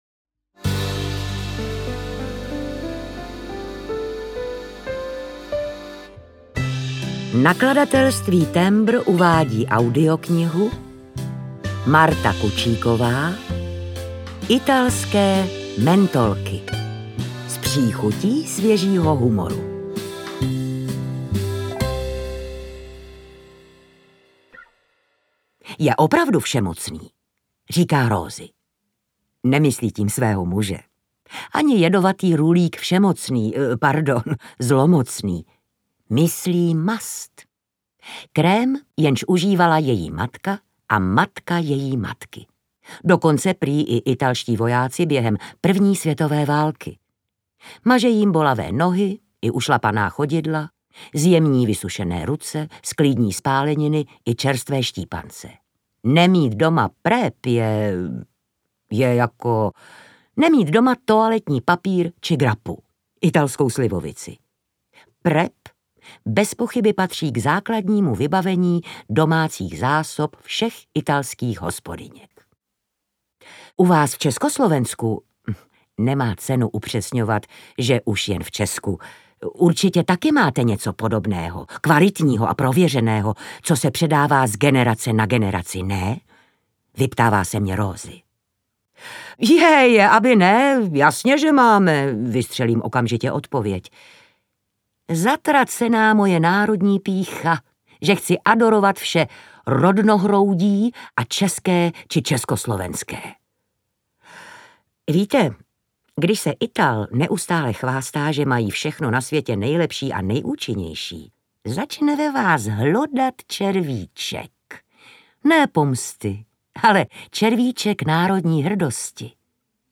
Italské mentolky: S příchutí svěžího humoru audiokniha
Ukázka z knihy
• InterpretLucie Juřičková